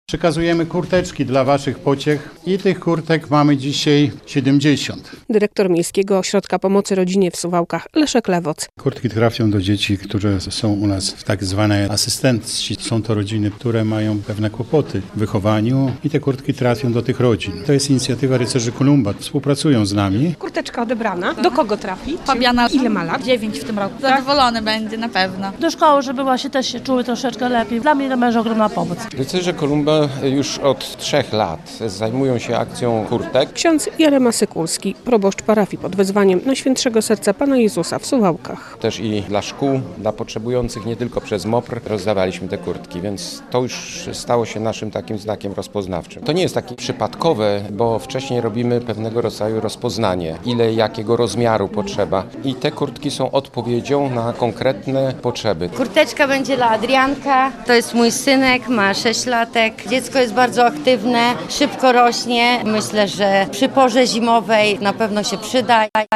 Potrzebujące dzieci z Suwałk otrzymały zimowe kurtki - relacja